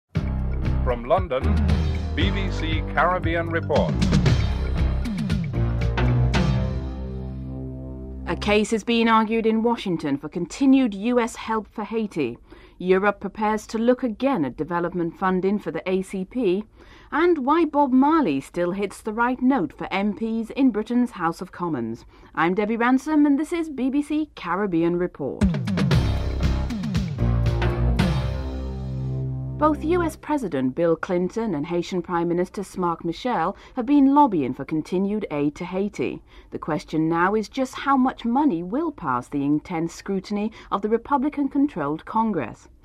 10. Sir Teddy Taylor gives his view on the European Union and what it has to offer Britain (12:15-14:56)